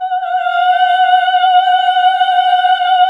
AAH F#3 -R.wav